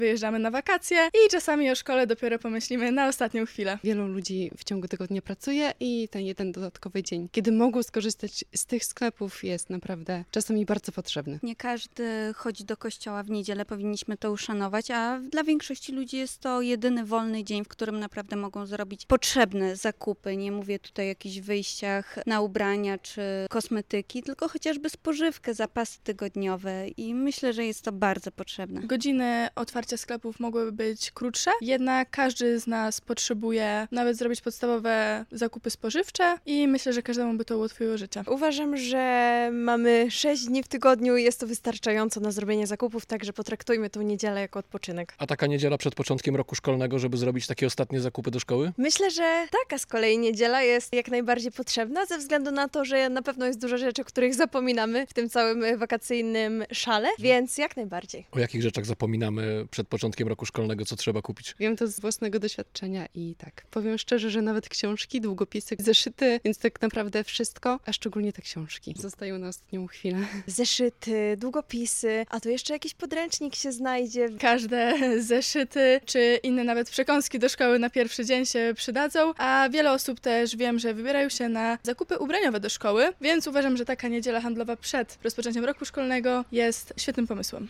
Zapytaliśmy mieszkańców Łomży, czy sklepy w niedzielę powinny być otwarte i co kupują przed 1 września.